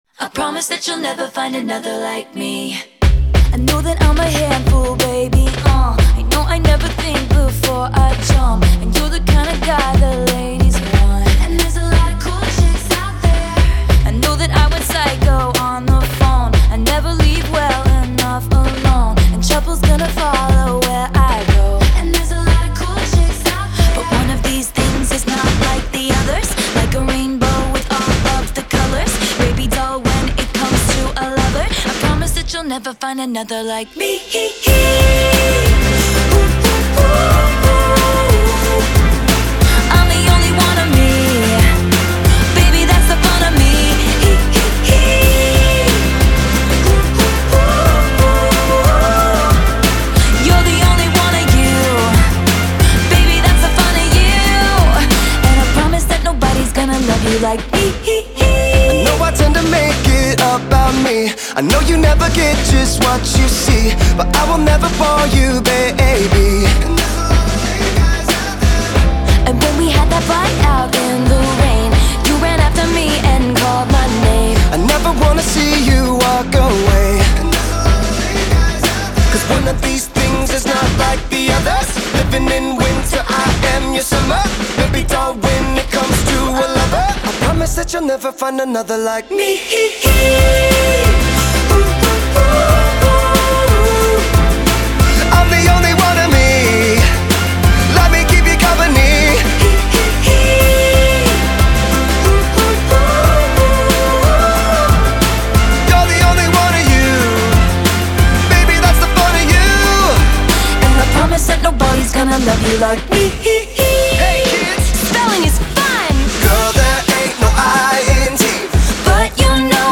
это яркий поп-гимн, наполненный оптимизмом и энергией.